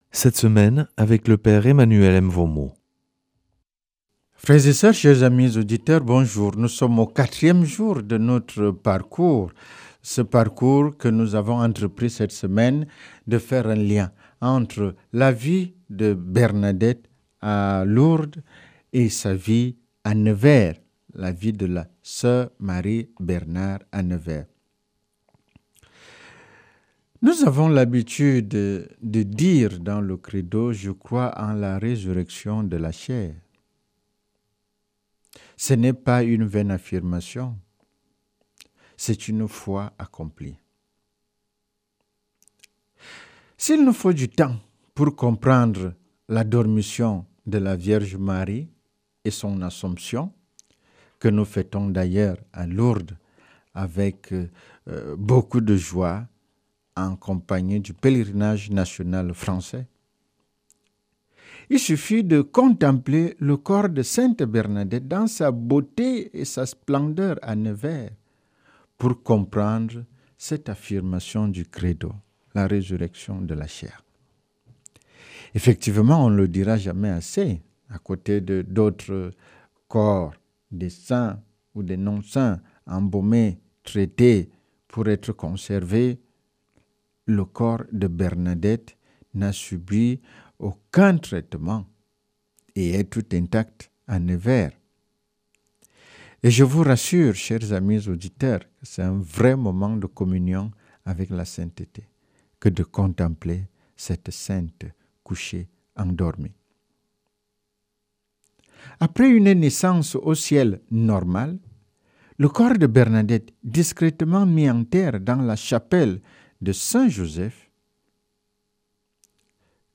jeudi 28 août 2025 Enseignement Marial Durée 10 min